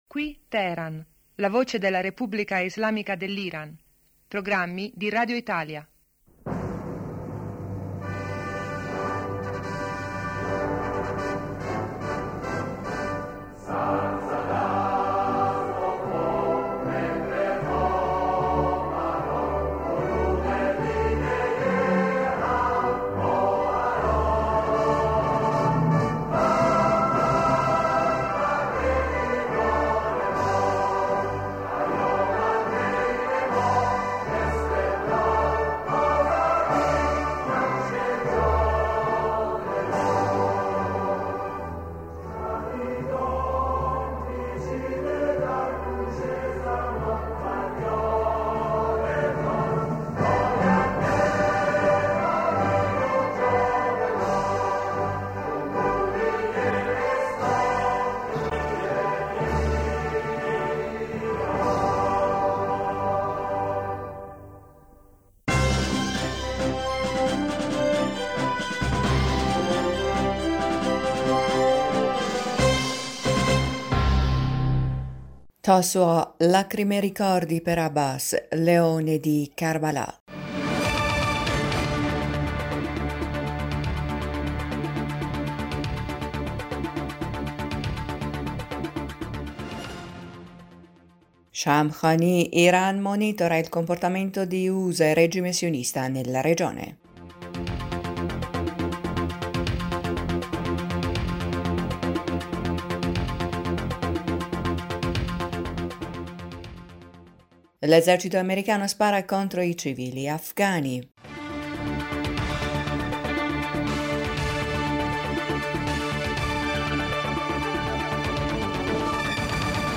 Ecco i titoli più importanti del nostro radiogiornale:1-Tasua, lacrime e ricordi per Abbas, leone di Karbalà,2-L’esercito americano spara contro i civili a...